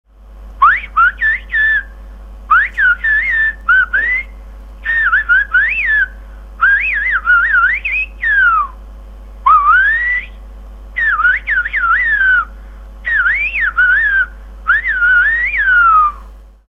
Eine Besonderheit auf La Gomera ist die Pfeifsprache El Silbo. Da Pfiffe viel weiter zu hören und zu unterscheiden sind als gesprochene (oder gar gebrüllte) Worte, hat sich hier im Laufe der Zeit eine Sprache entwickelt, mit der sich die Bewohner der abgelegenen Höfe oder Hirten auf den Weiden über große Entfernungen unterhalten konnten.
Manche Sätze klingen fast so wie ihre spanische Übersetzung.
silbo.mp3